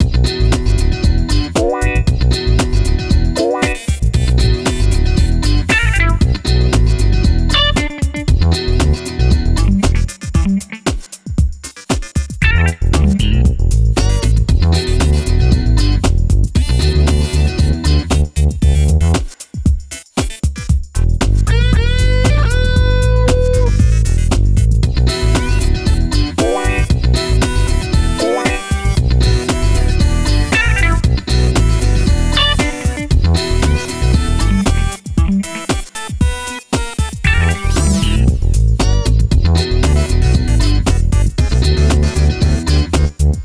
Tecno funk (bucle)
funk
melodía
repetitivo
rítmico
sintetizador